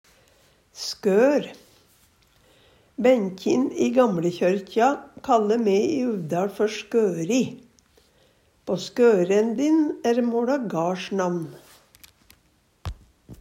skør - Numedalsmål (en-US)